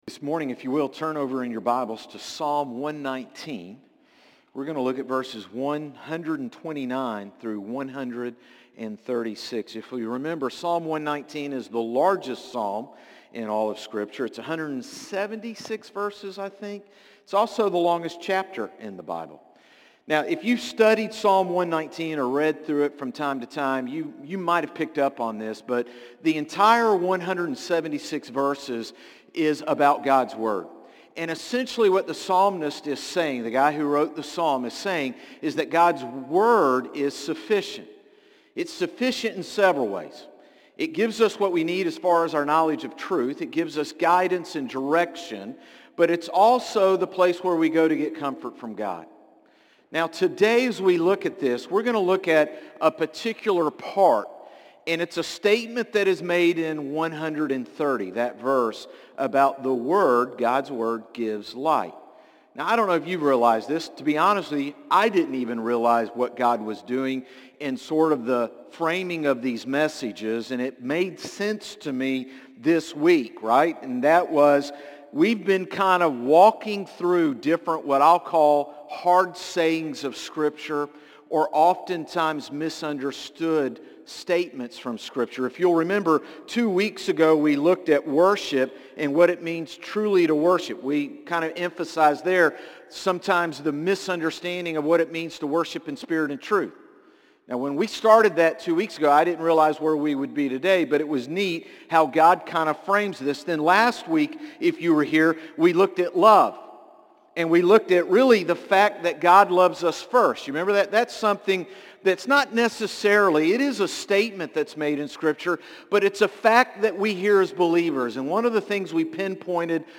Sermons - Concord Baptist Church
Morning-Service-8-18-24.mp3